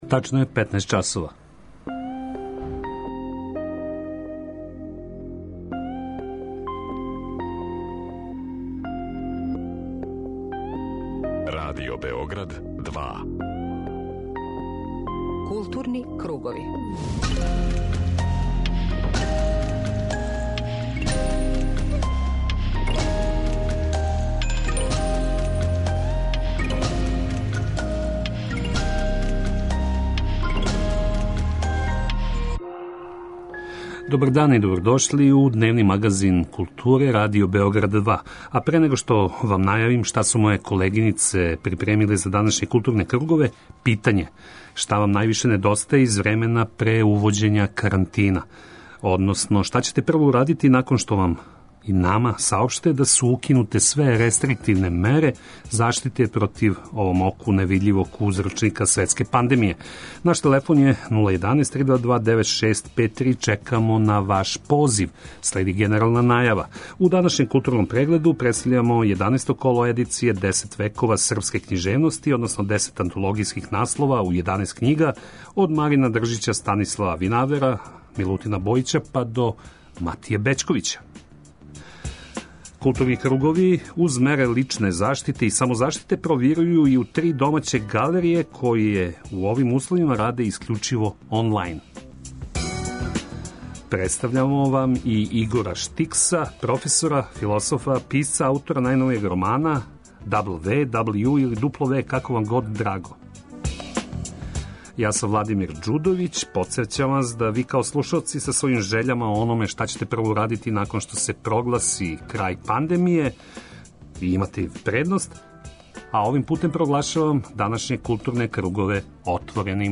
Доносимо и разговор